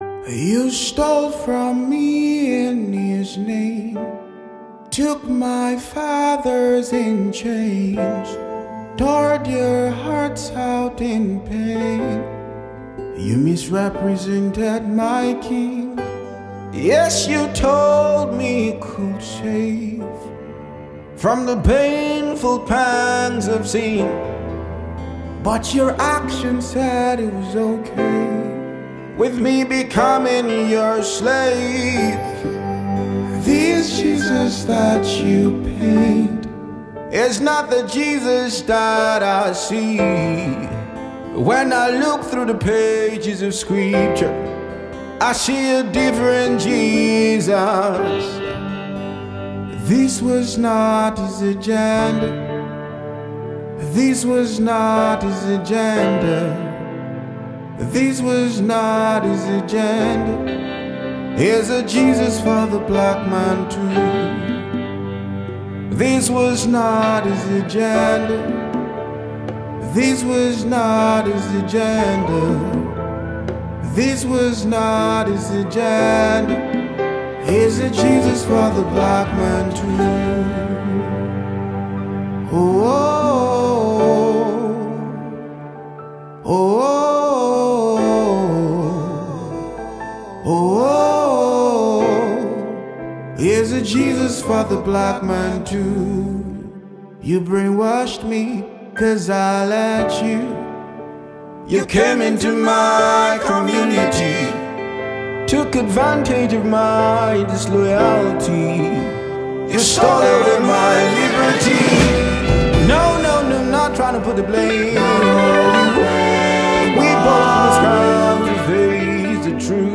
the one referred to as “Afro-Gospel”.